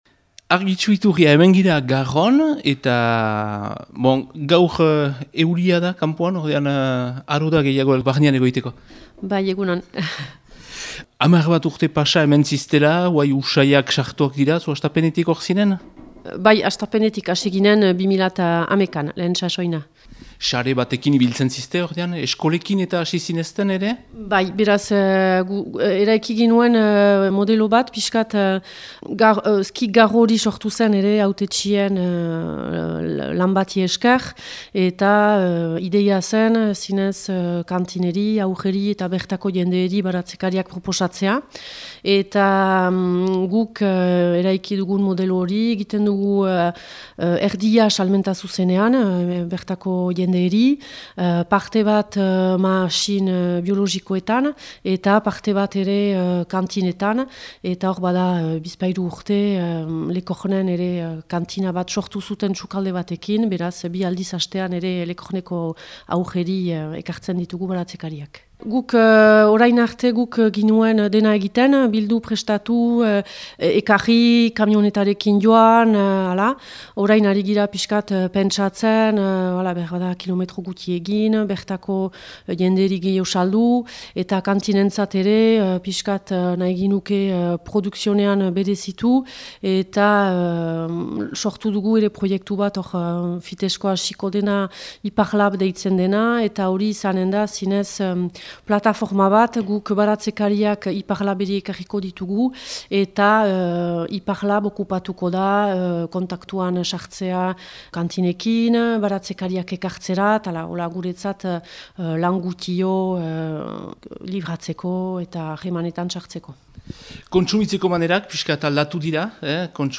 BERRIAK